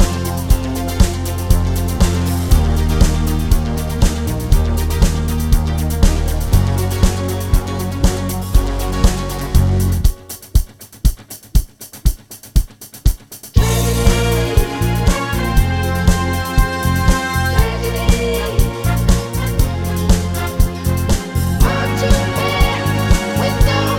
One Semitone Down Pop (1960s) 4:58 Buy £1.50